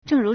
正如 zhèngrú
zheng4ru2.mp3